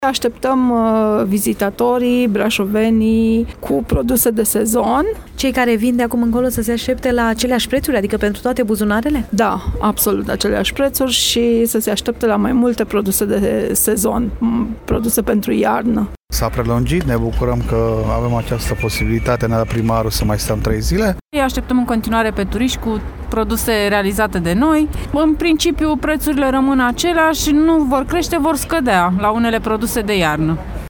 Ba mai mult, o parte dintre ei îți reînnoiesc marfa și mai scad și prețurile:
VOX-targ-craciun-brasov-1.mp3